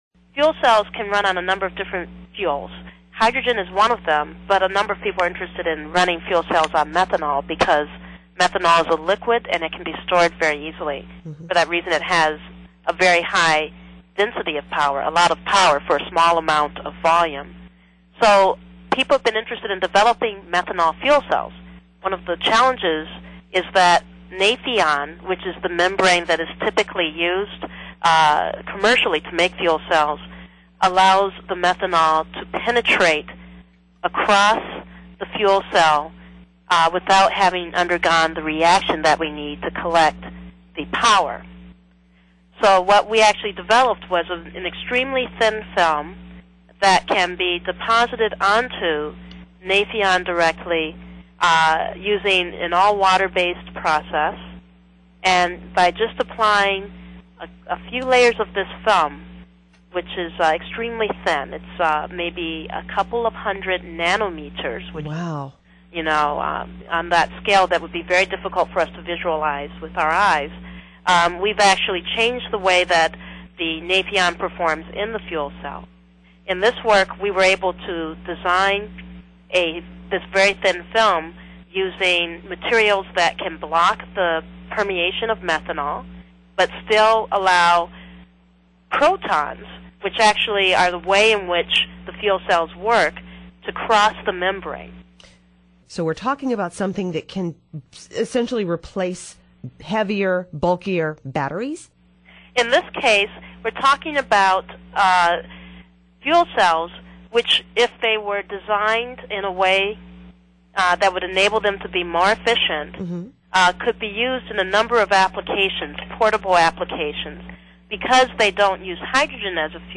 Full interview (.wma)